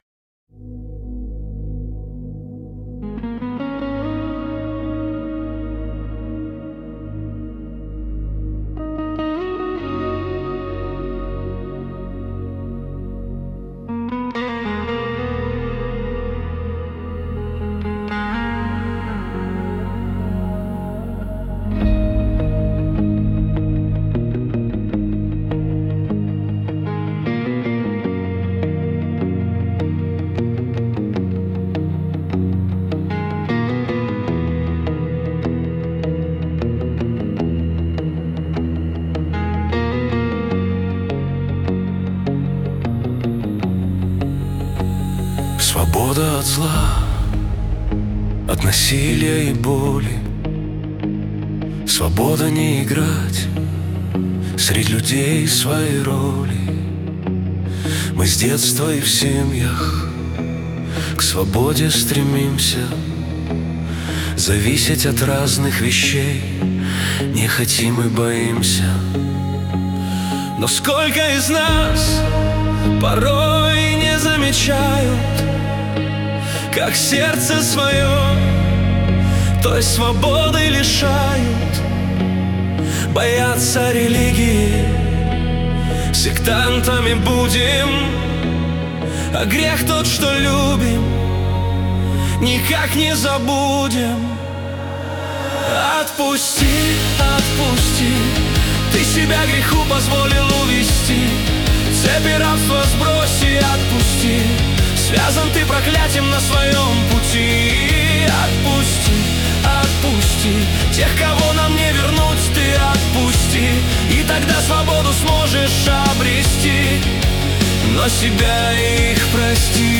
14 просмотров 46 прослушиваний 5 скачиваний BPM: 77